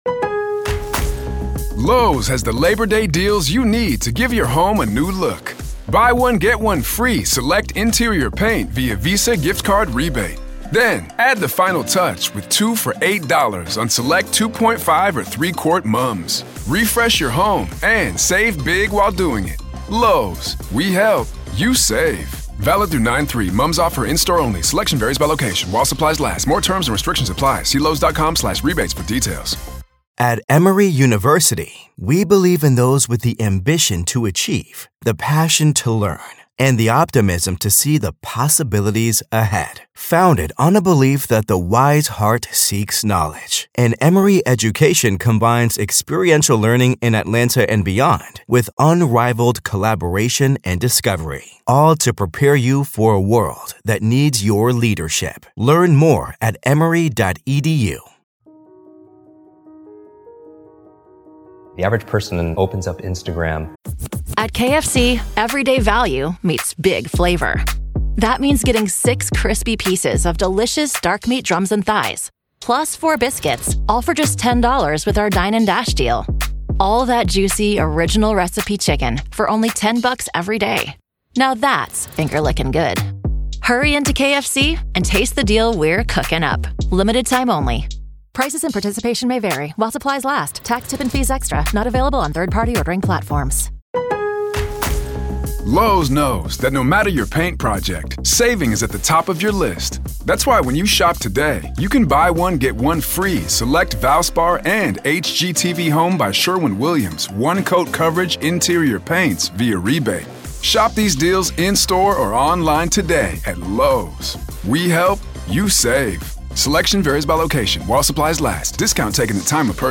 Credit to Speaker: Jim Kwik